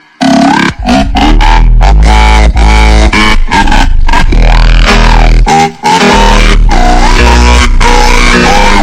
Wet Fart Oi Oi Oi